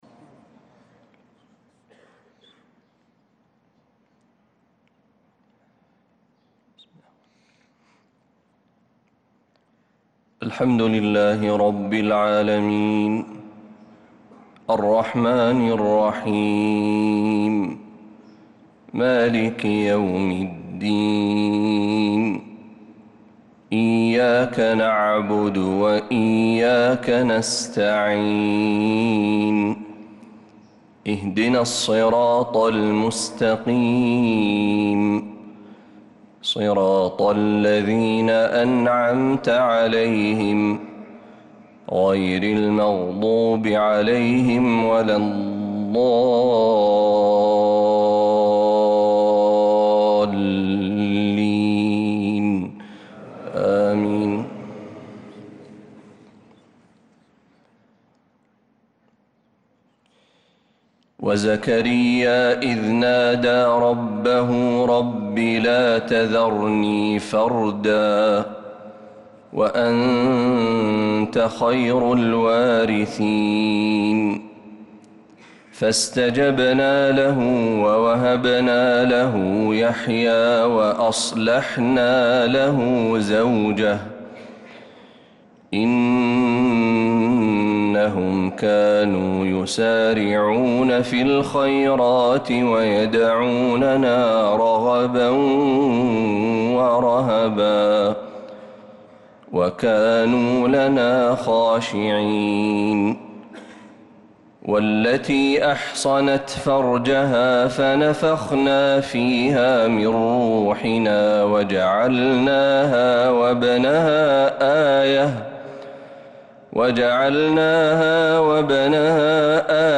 صلاة الفجر للقارئ محمد برهجي 21 رجب 1446 هـ